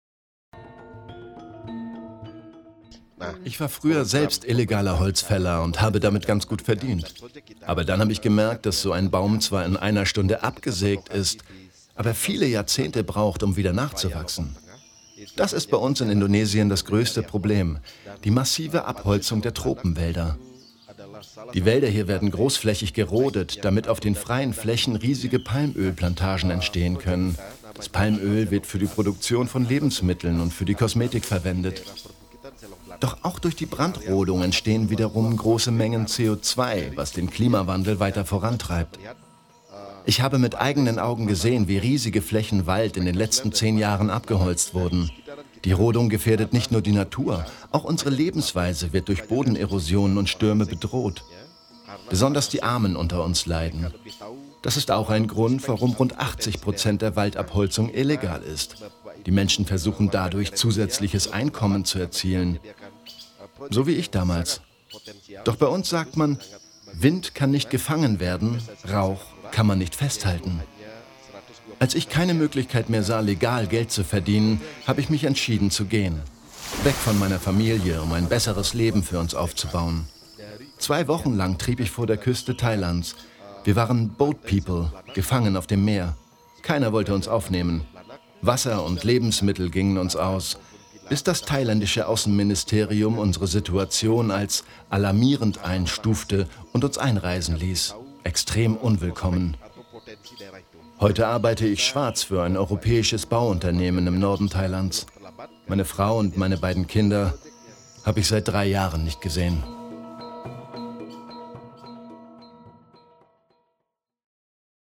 Ein Holzfäller erzählt: